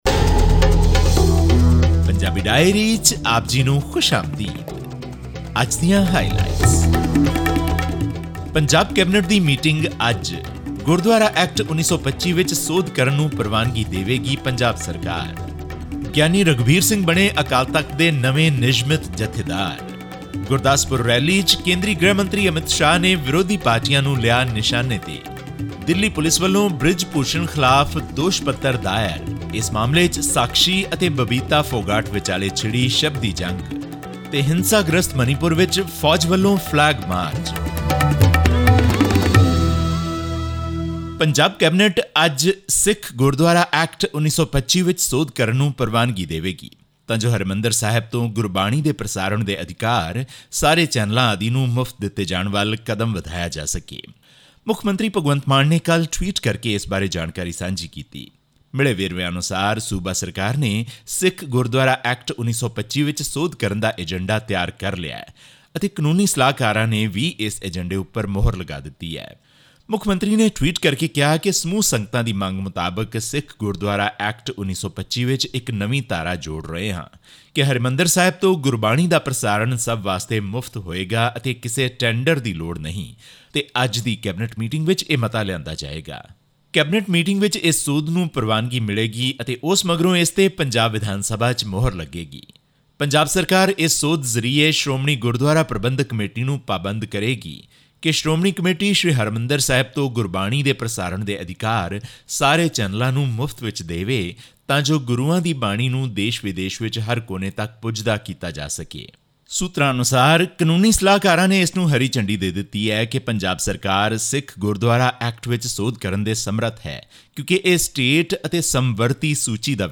ਇਸ ਖ਼ਬਰ ਅਤੇ ਪੰਜਾਬ ਨਾਲ ਸਬੰਧਤ ਹੋਰ ਖ਼ਬਰਾਂ ਦੇ ਵੇਰਵੇ ਜਾਣਨ ਲਈ ਸੁਣੋ ਪੰਜਾਬੀ ਡਾਇਰੀ ਦੀ ਰਿਪੋਰਟ।